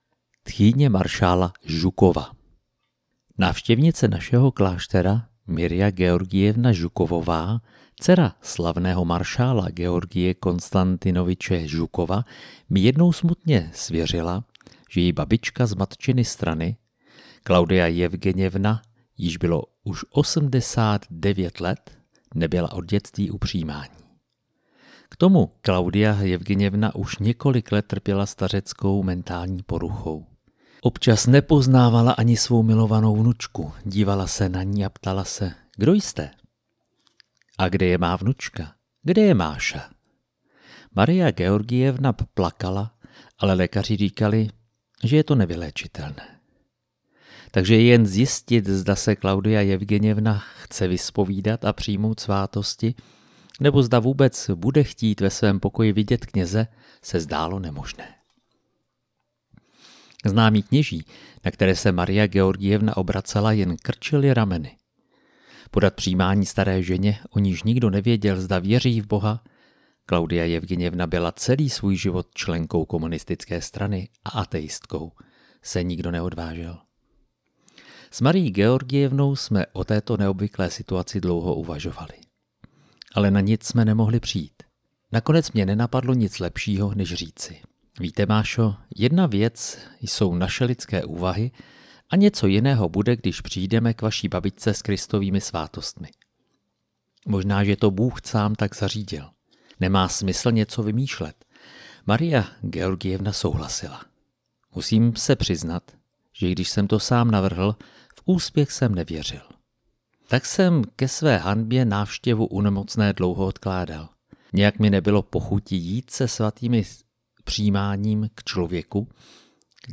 amluvili jsme pro vás ukázku, no spíš ochutnávku, jedné kapitoly z knihy Nesvatí svatí a jiné příběhy od metropolity Tichona Ševkunova.